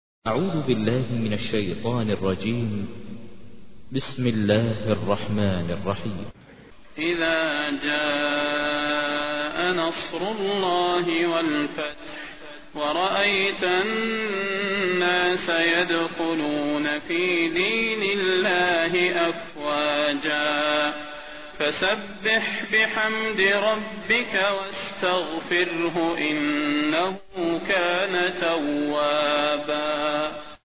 تحميل : 110. سورة النصر / القارئ ماهر المعيقلي / القرآن الكريم / موقع يا حسين